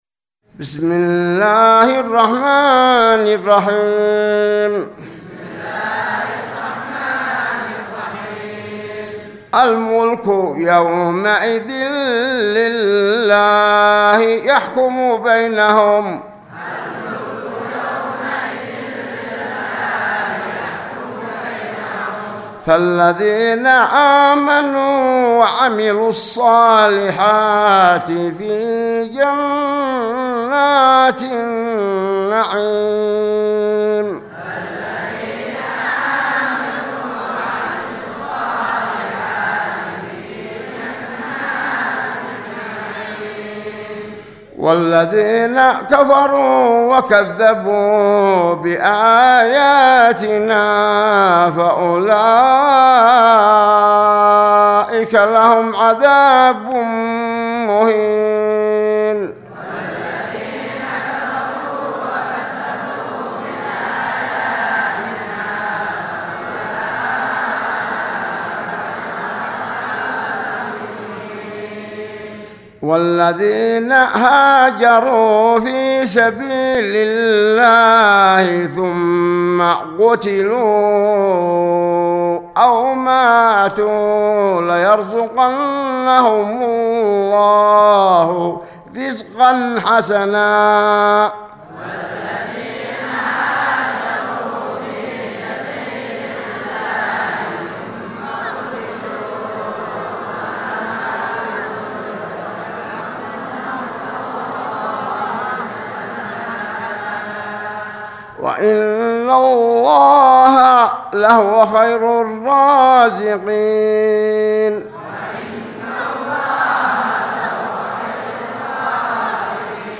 سورة الحج مع الترديد من56 الى 64